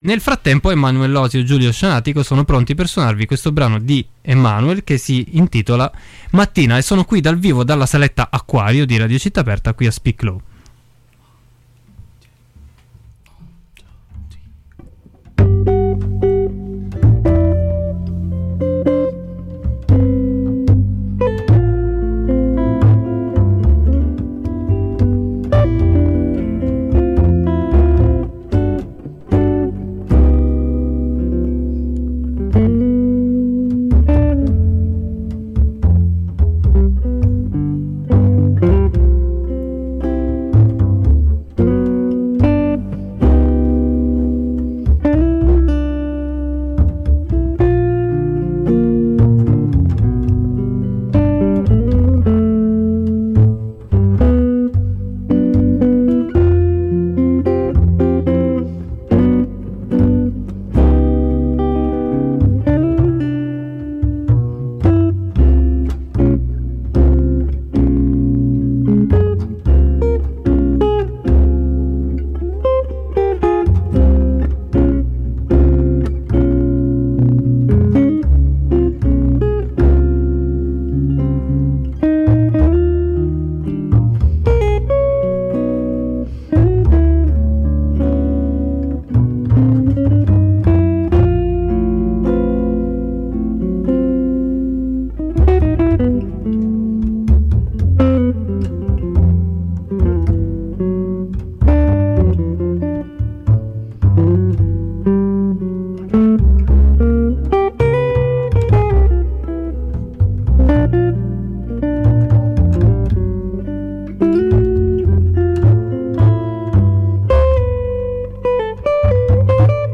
suonano dal vivo dalla Saletta Acquario